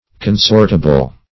Definition of consortable.
Search Result for " consortable" : The Collaborative International Dictionary of English v.0.48: Consortable \Con*sort"a*ble\ (k[o^]n*s[^o]rt"[.a]*b'l), a. Suitable for association or companionship.